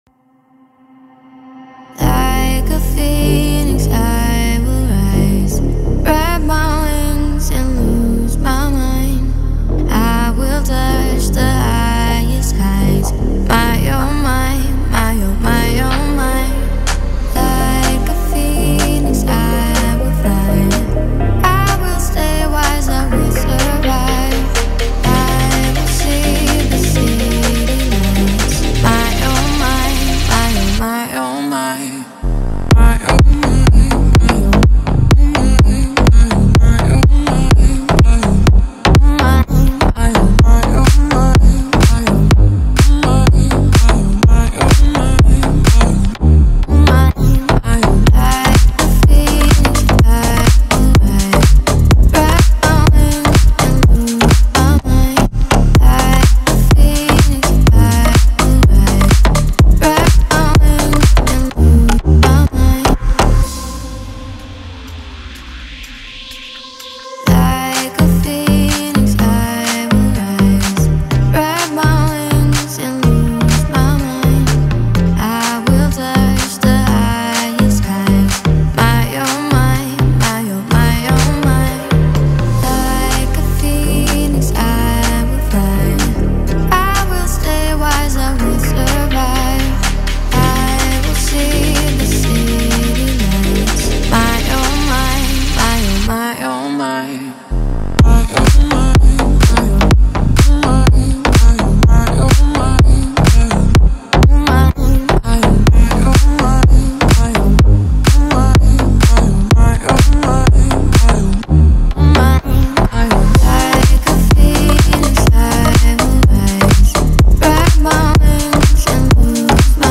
Звучание отличается яркими вокалами